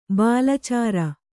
♪ bālacāra